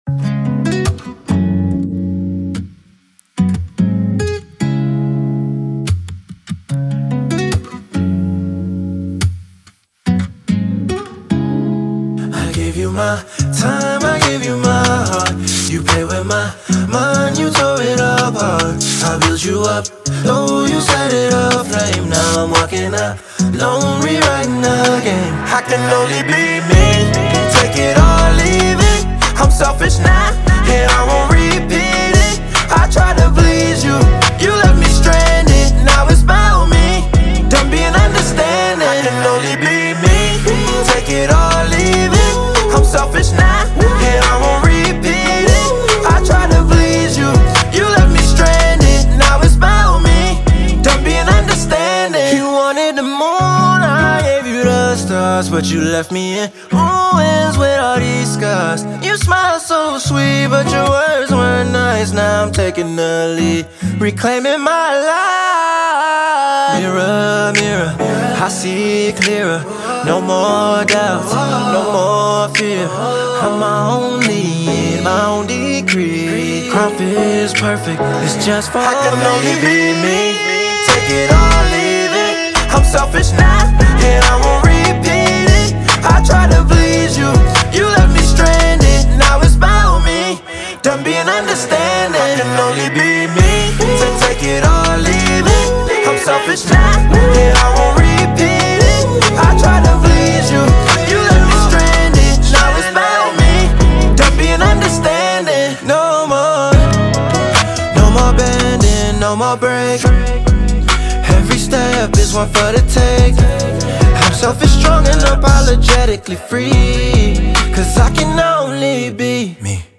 Hiphop
soulful & infectious music single